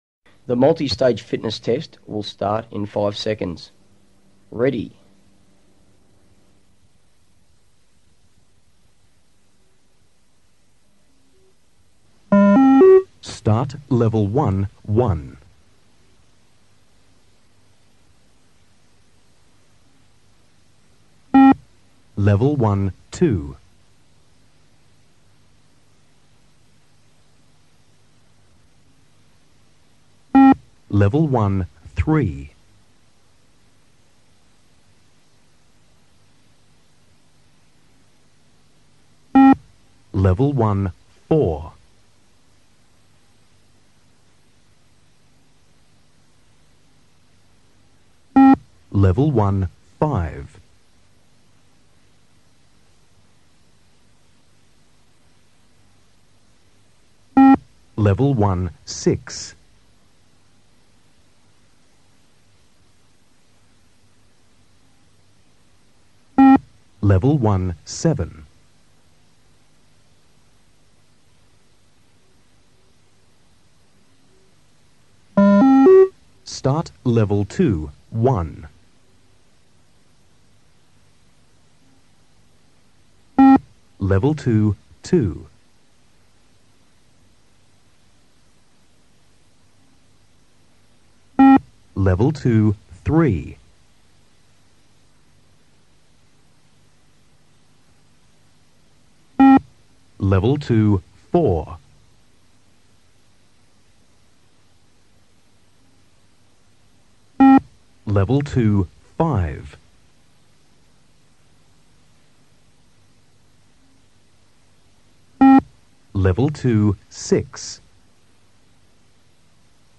Merupakan tes yang dilakukan dengan cara berlari secara terus menerus dari satu titik/garis ke titik/ garis lainnya dengan jarak 20 m mengikuti mengikuti irama suara beep/ketukan sebagai isyarat.
The-Beep-Test-audio.mp3